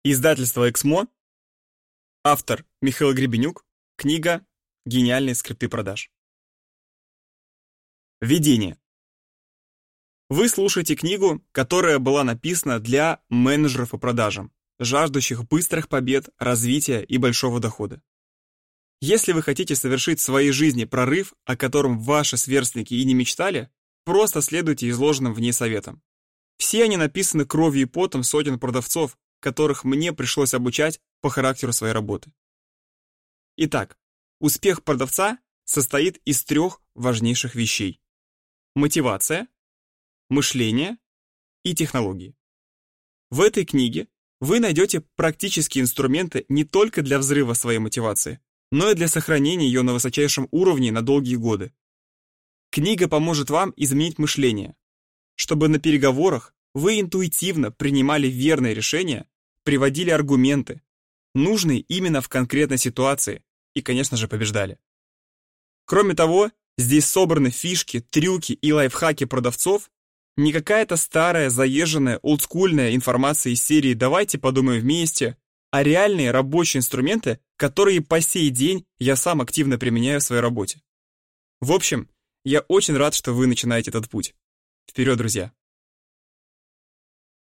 Аудиокнига Гениальные скрипты продаж. Как завоевать лояльность клиентов. 10 шагов к удвоению продаж | Библиотека аудиокниг